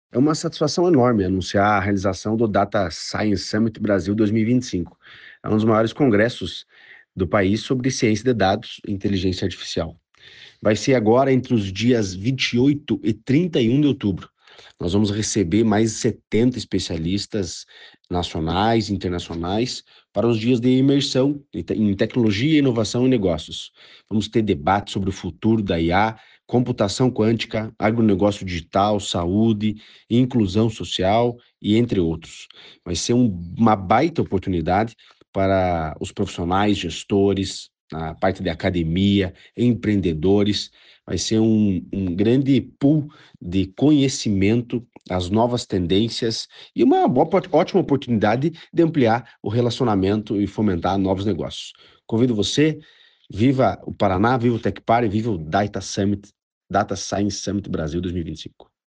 Sonora do diretor-presidente do Tecpar, Eduardo Marafon, sobre o Data Science Summit Brasil 2025